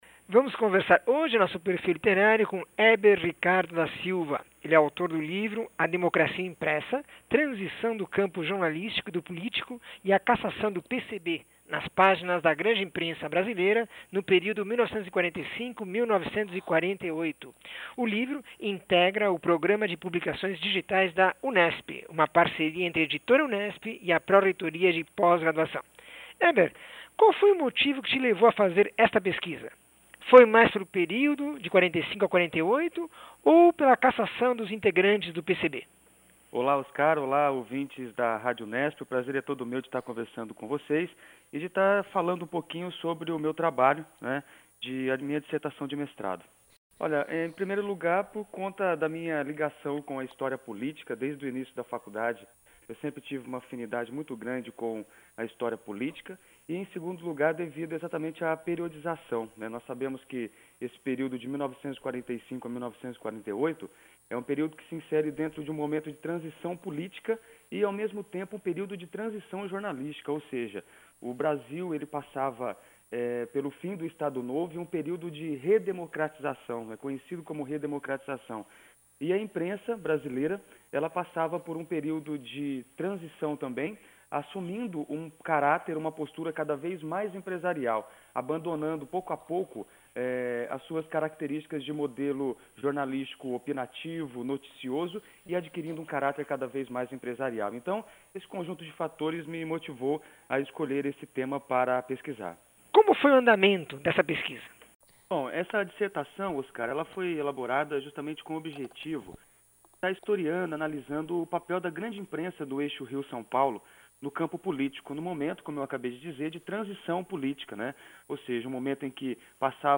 entrevista 615